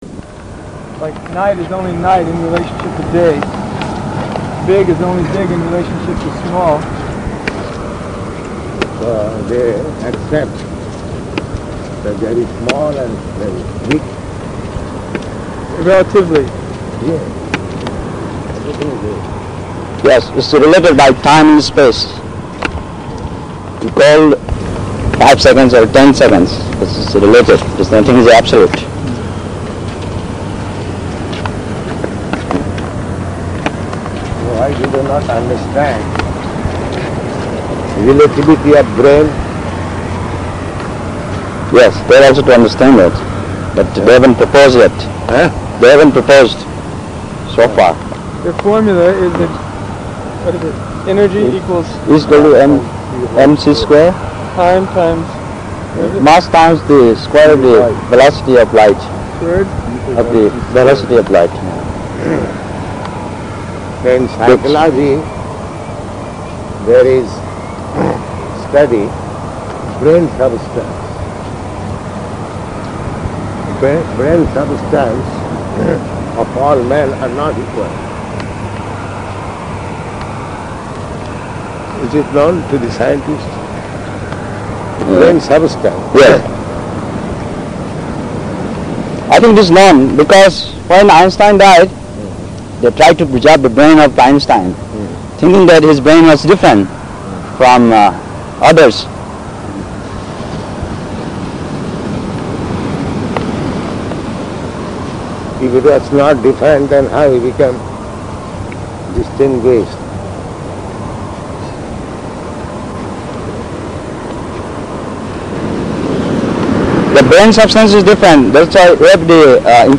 -- Type: Walk Dated: September 23rd 1972 Location: Los Angeles Audio file